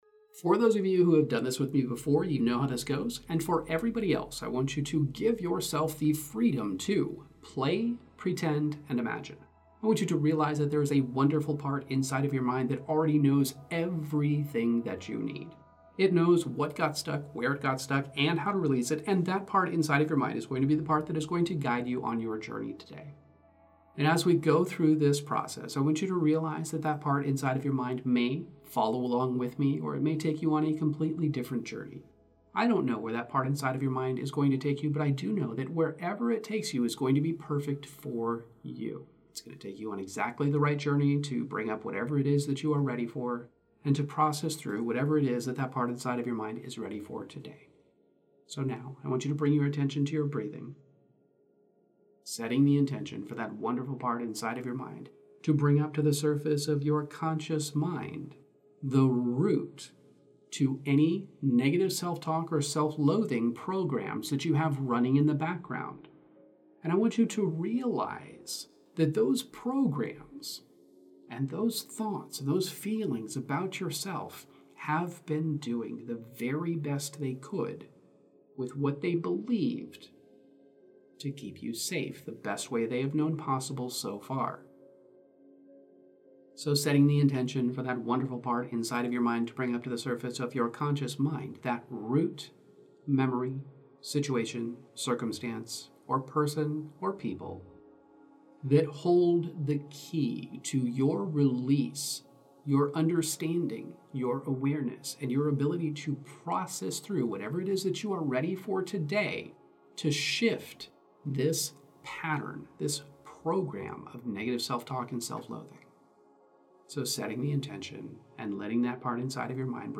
This guided hypnosis meditation is basically like a mental detox, scrubbing away all those harsh self-criticisms and replacing them with feel-good vibes. It's a chill session where you dive deep into your brain to figure out why you're so hard on yourself and then, with a bit of imagination magic, you get to turn that around.